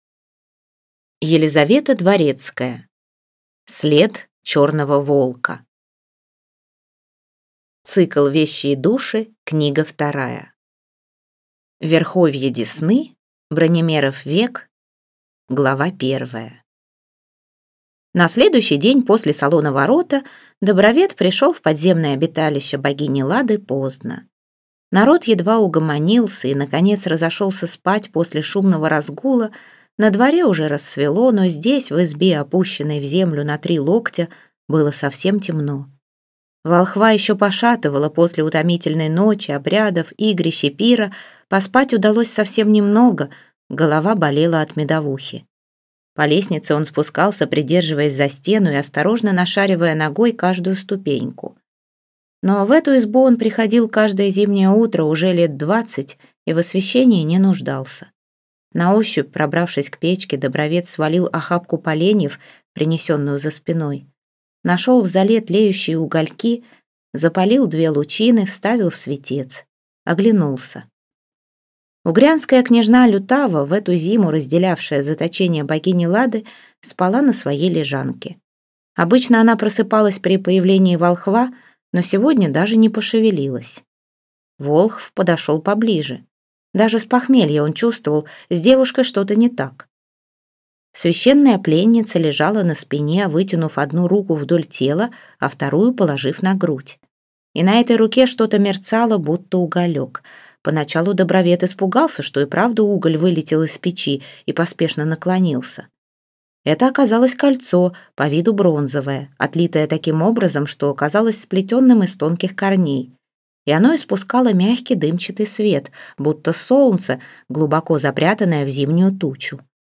Аудиокнига След черного волка | Библиотека аудиокниг